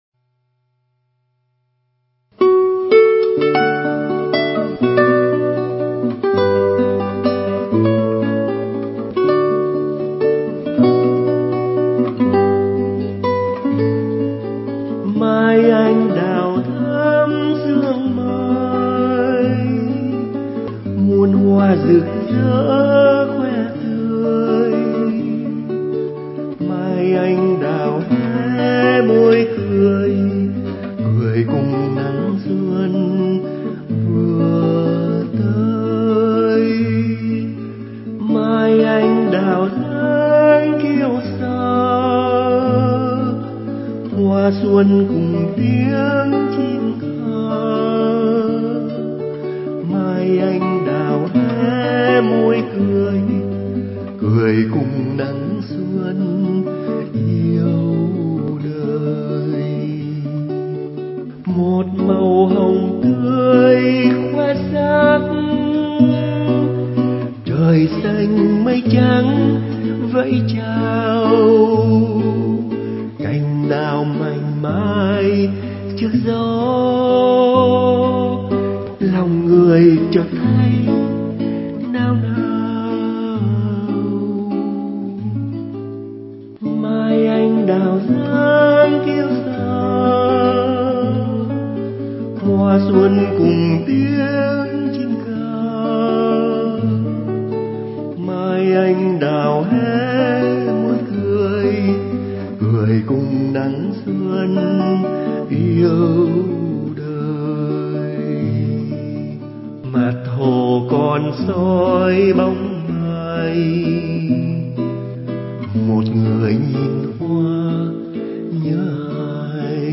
Tác giả đàn và hát